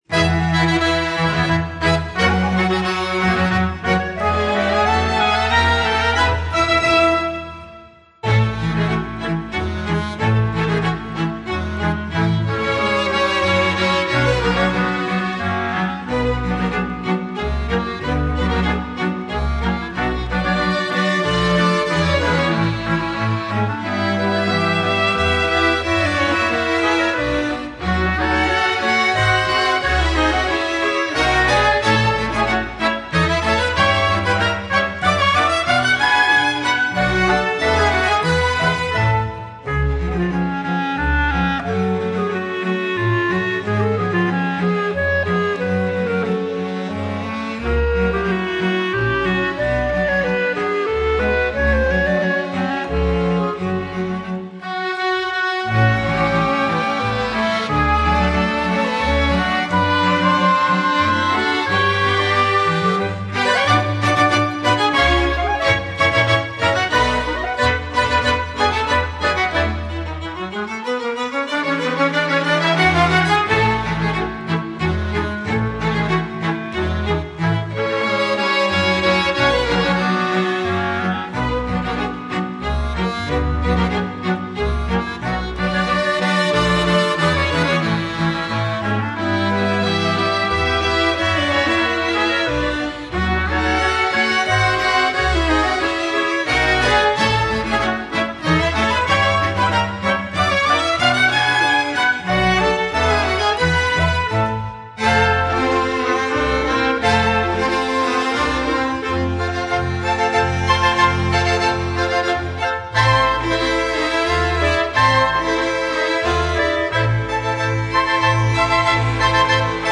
flet
trąbka
altówka
wiolonczela
kontrabas
akordeon.